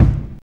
VINYL 4.wav